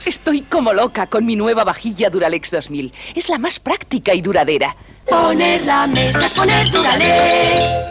Publicitat de la vaixella Duralex 2000 Gènere radiofònic Publicitat